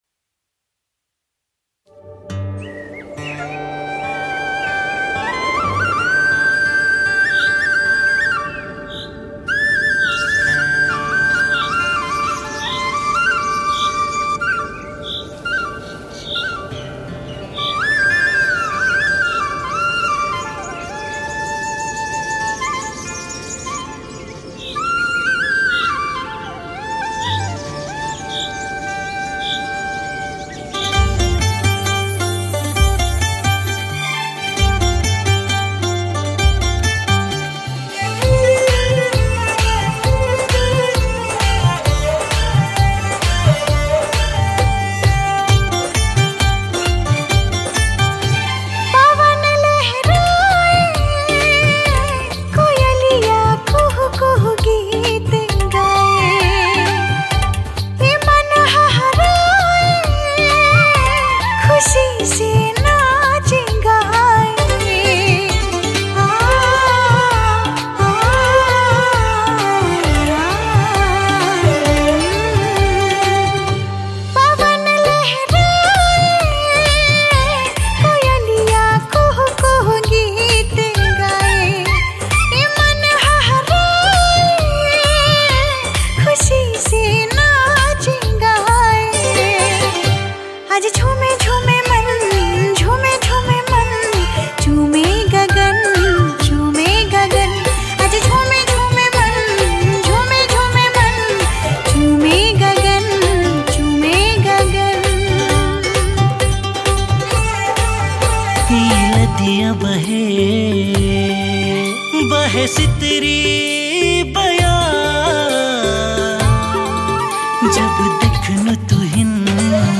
New Tharu Song 2023
Tharu Romantic Song